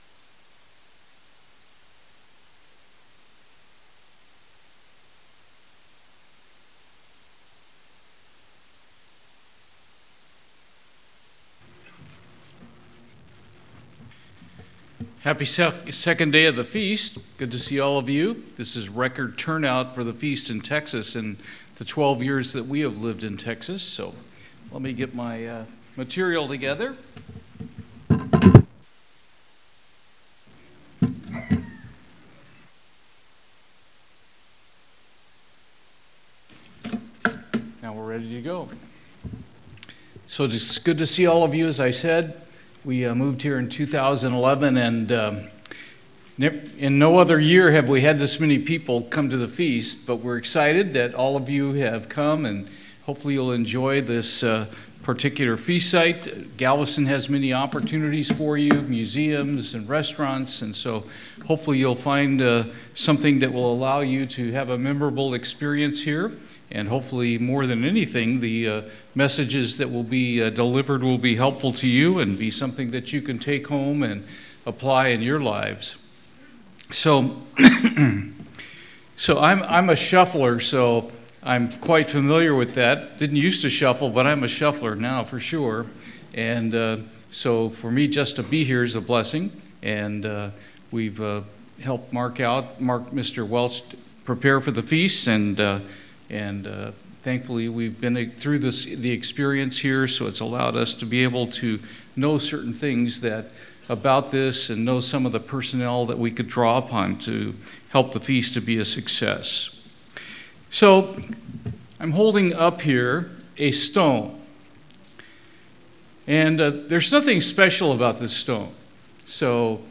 This sermon was given at the Galveston, Texas 2023 Feast site.